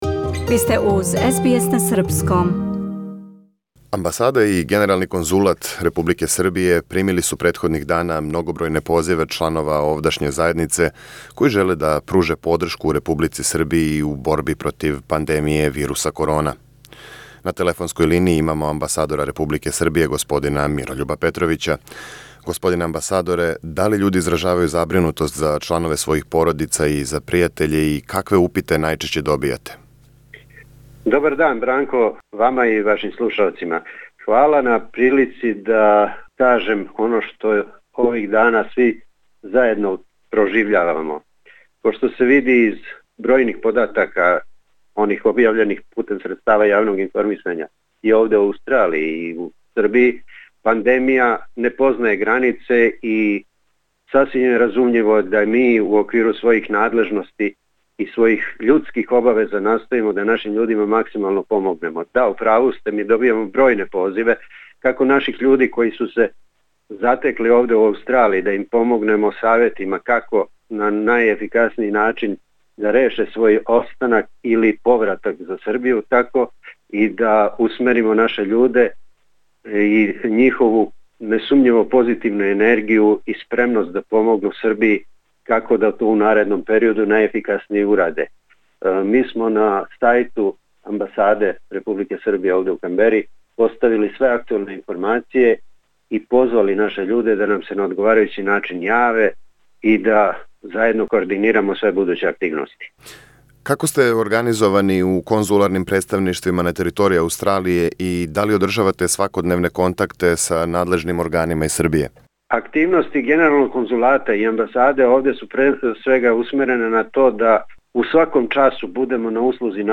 Амбасада и генерални конзулат Републике Србије свакодневно добијају на десетине позива српских држављана на привременим визама у Аустралији, као и чланова овдашње заједнице који желе да пруже подршку и помоћ Србији у борби против вируса корона. Амбасадор Мирољуб Петровић говори о активностима српских конзуларних представништава на том плану.